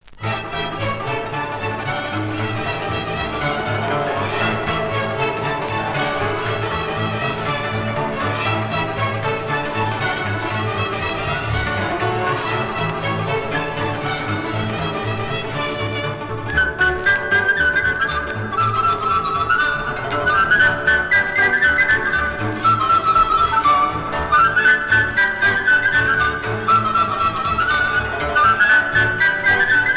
danse : sîrba (Roumanie)
Pièce musicale éditée